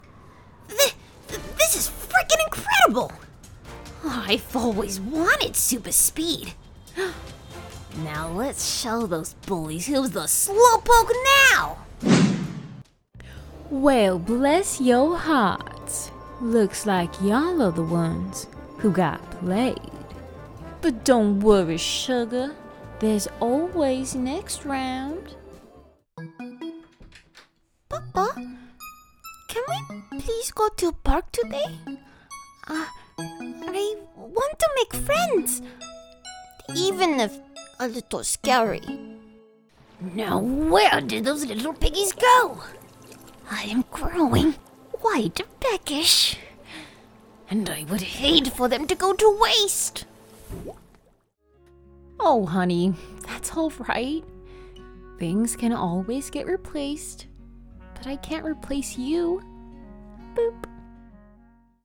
American, Midwestern, Southern, British, Irish, German, Spanish
Character Demo.mp3